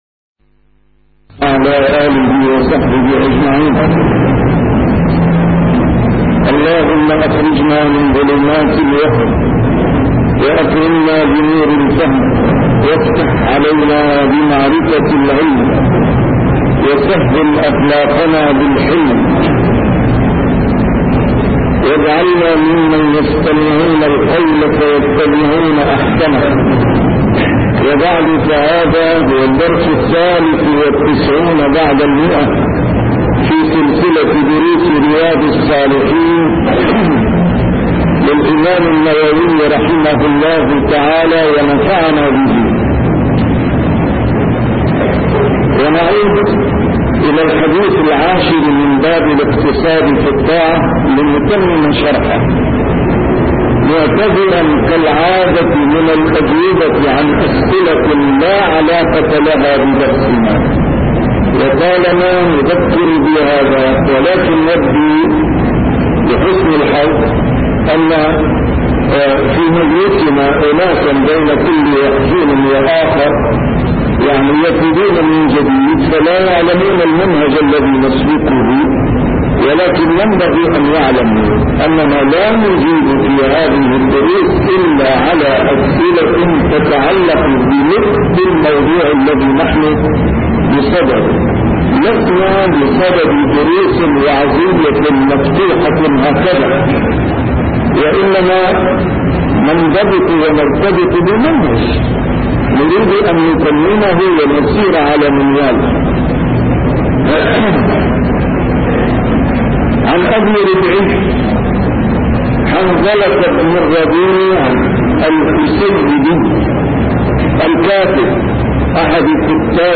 A MARTYR SCHOLAR: IMAM MUHAMMAD SAEED RAMADAN AL-BOUTI - الدروس العلمية - شرح كتاب رياض الصالحين - 193- شرح رياض الصالحين: الاقتصاد في العبادة